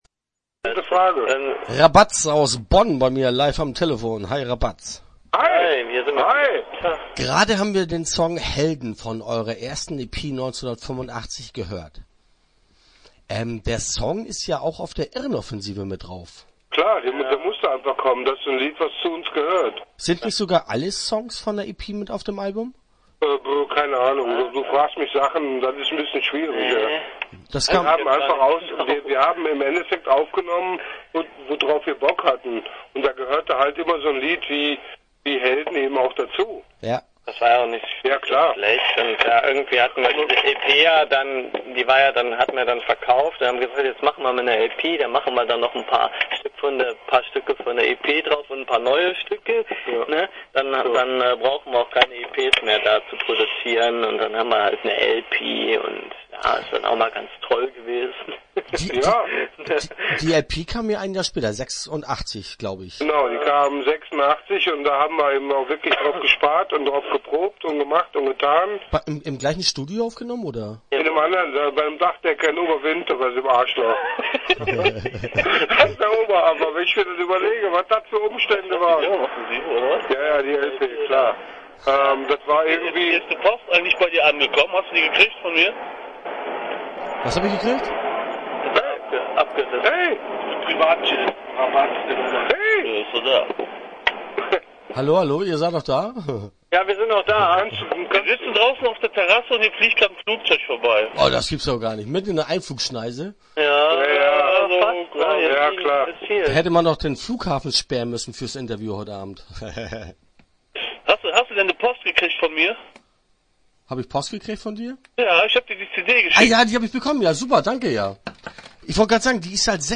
Interview Teil 1 (12:17)